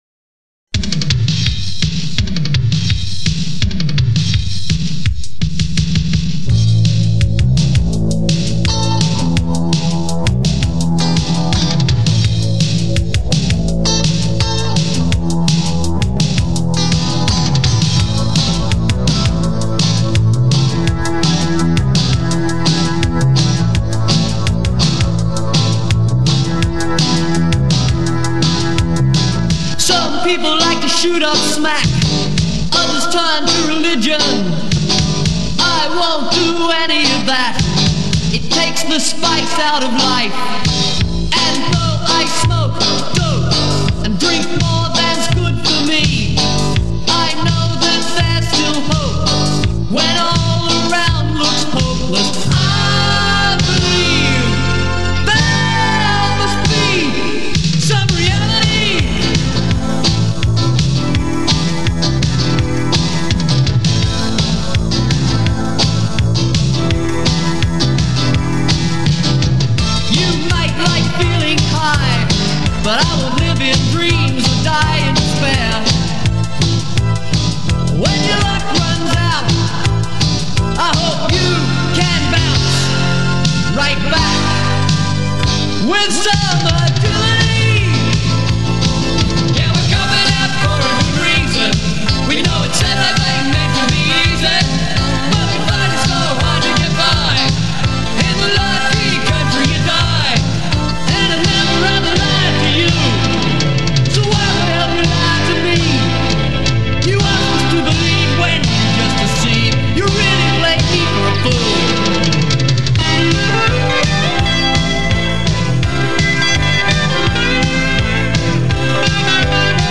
lead guitar, harmonica and backing vocals